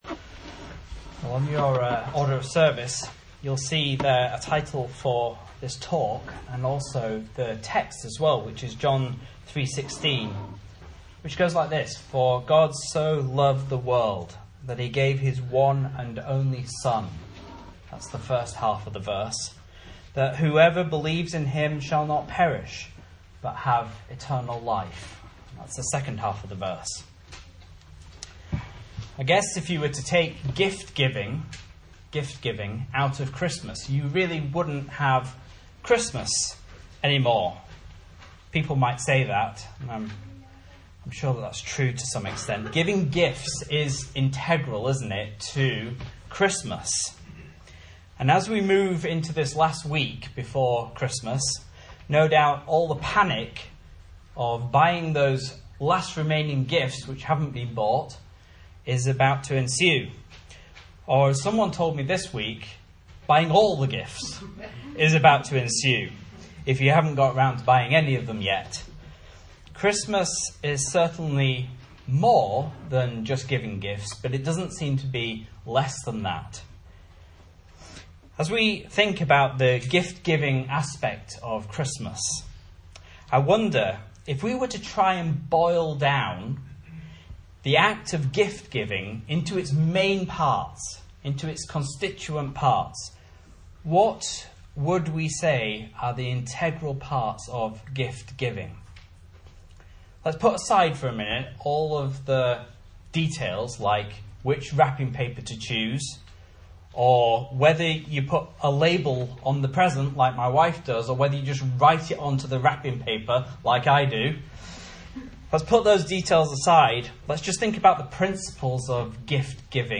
Message Scripture: John 3:16 | Listen